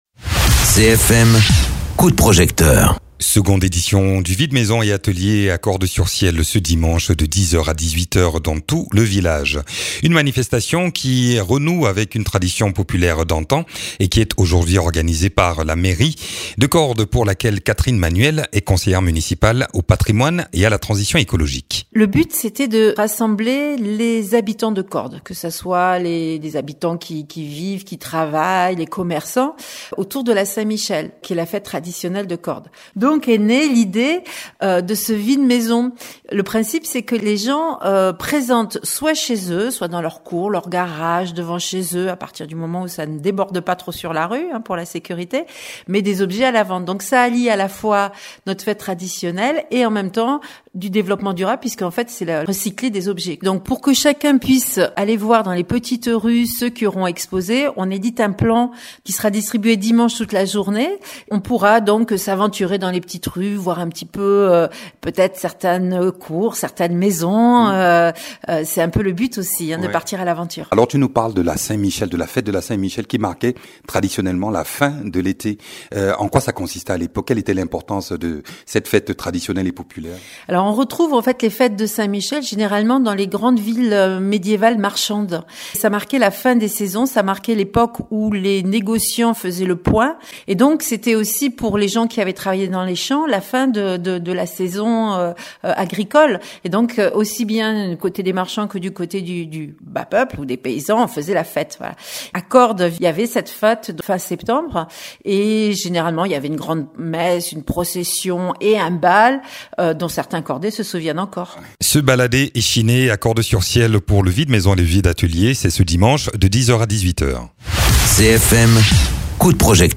Interviews
Invité(s) : Catherine Manuel, conseillère municipale de Cordes-sur-ciel.